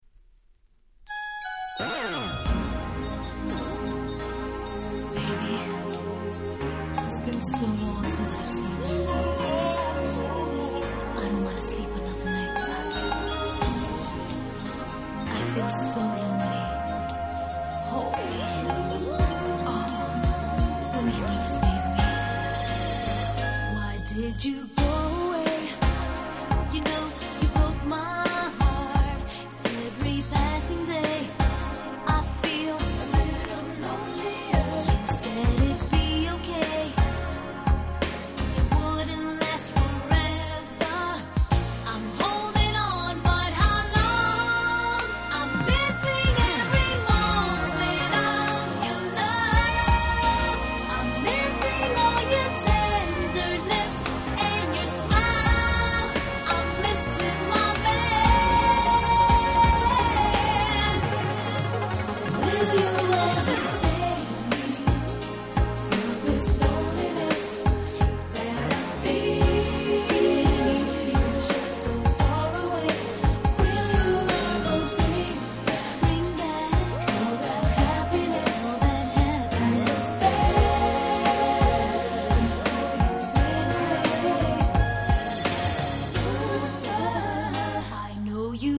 93' Nice Mid R&B !!
落ち着いた雰囲気のムーディーなMidで凄く良い曲です！